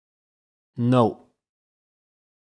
دانلود آهنگ نو از افکت صوتی انسان و موجودات زنده
جلوه های صوتی